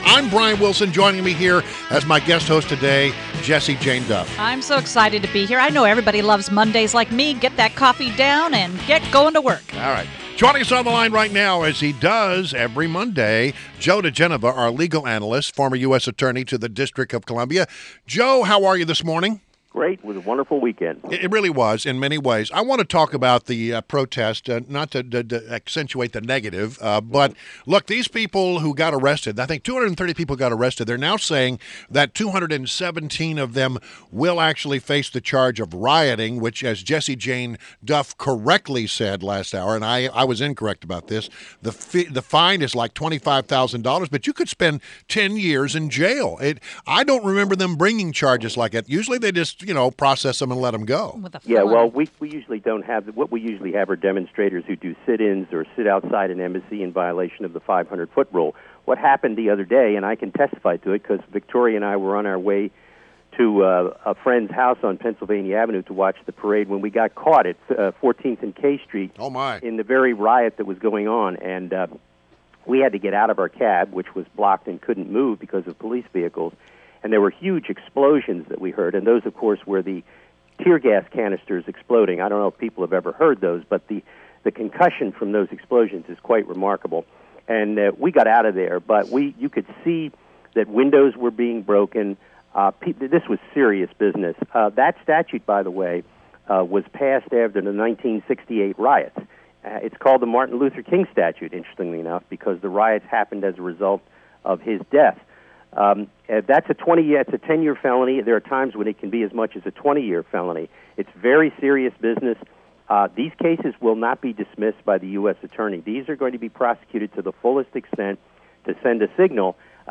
INTERVIEW — JOE DIGENOVA – legal analyst and former U.S. Attorney to the District of Columbia